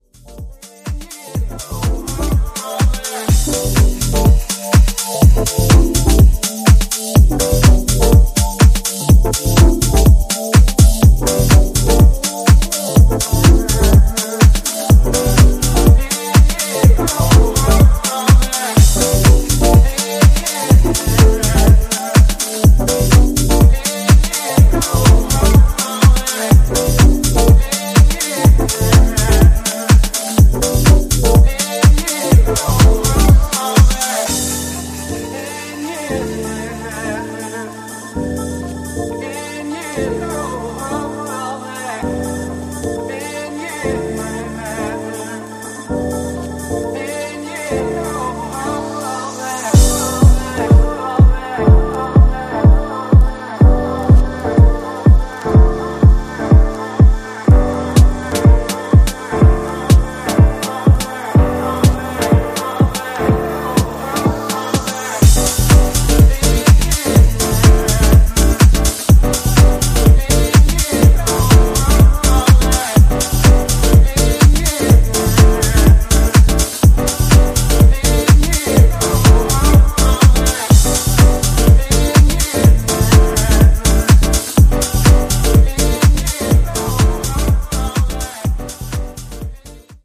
ジャンル(スタイル) DISCO HOUSE